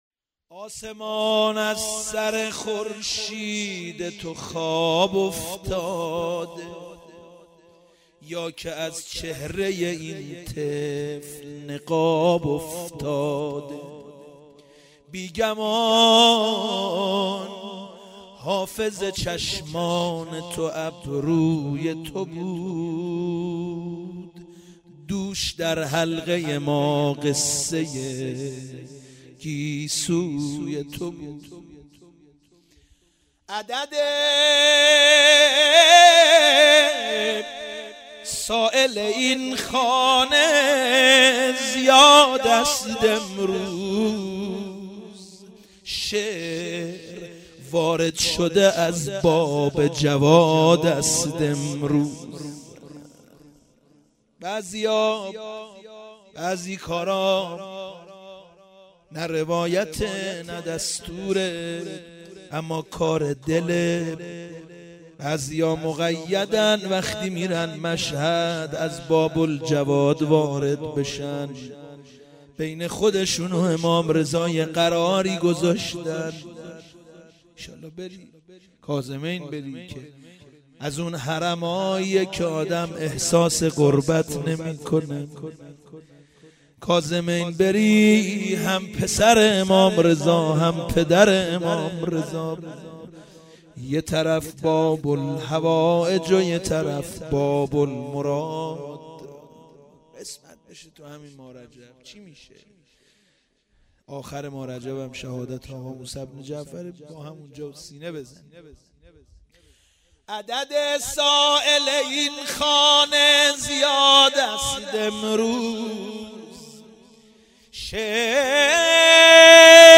صوت/ گلچین مولودی ولادت حضرت جوادالائمه(ع) و حضرت علی‌اصغر(ع)
همزمان با فرارسیدن ولادت حضرت امام جواد علیه السلام و حضرت علی اصغر علیه السلام، فایل صوتی گلچین مولودی با نوای مداحان اهل بیت (ع) را می شنوید.